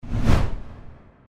impact1.mp3